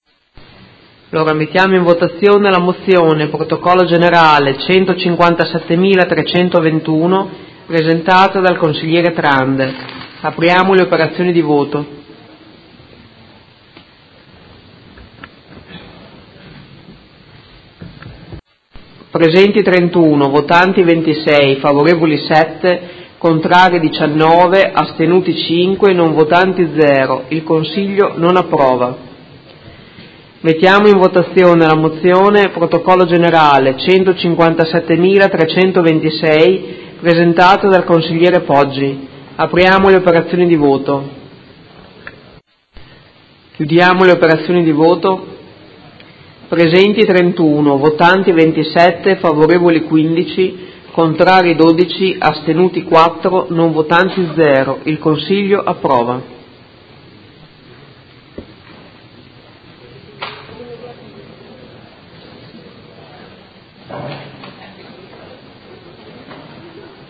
Presidentessa
Seduta del 19/10/2017 Mette ai voti la Mozione n. 157321 Presentata in corso di seduta: Il progetto di legge disciplina regionale sulla tutela ed uso del territorio, presenta un evidente divaricazione tra obiettivi dichiarati, azioni e previsioni in particolare sul saldo zero di consumo di suolo, sul ruolo dei grandi investitori privati e sulla semplificazione simmetrica, sulla permanenza della semplificazione e sul ruolo dei Consigli Comunali.